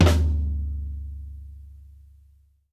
SNARE+FLOO-R.wav